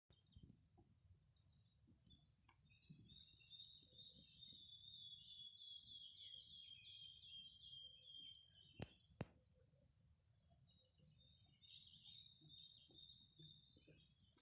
Putni -> Ķauķi ->
Svirlītis, Phylloscopus sibilatrix
StatussDzied ligzdošanai piemērotā biotopā (D)